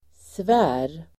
Uttal: [svä:r]